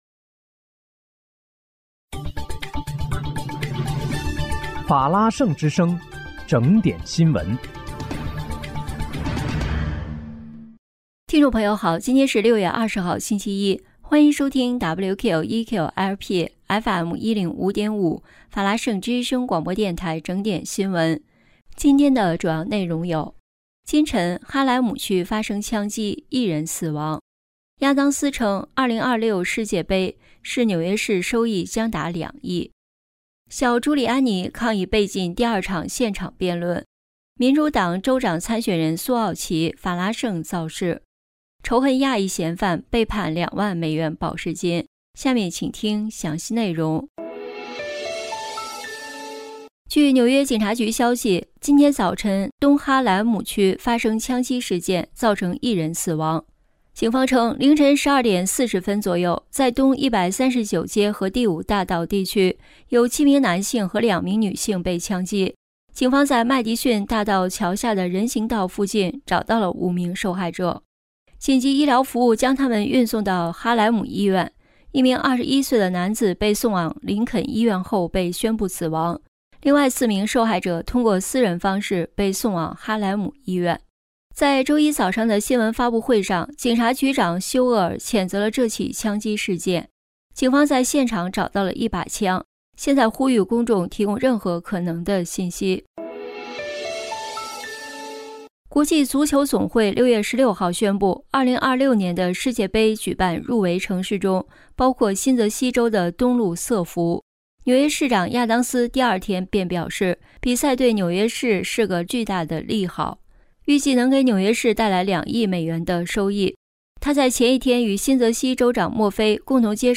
6月20日（星期一）纽约整点新闻
在听众朋友您好！今天是6月20号，星期一，欢迎收听WQEQ-LP FM105.5法拉盛之声广播电台整点新闻。